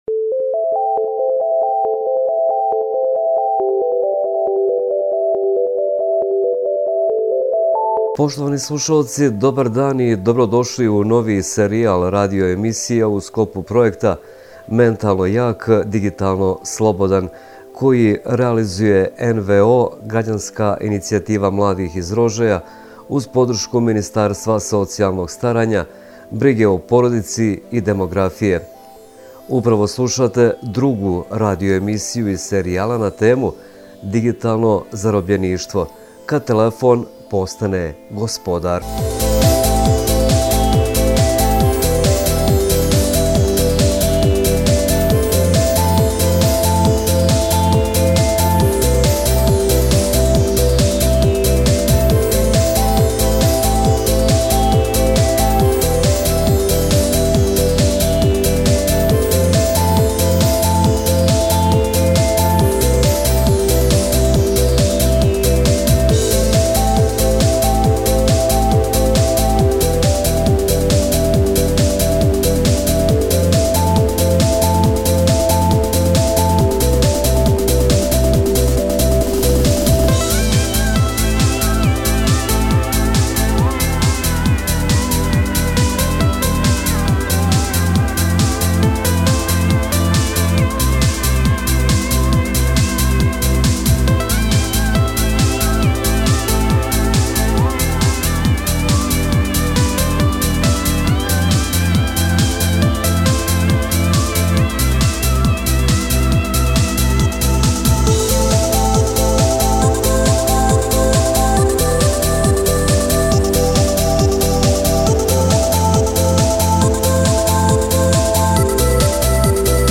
Radio emisija: Digitalno zarobljeništvo – Mladi Rožaja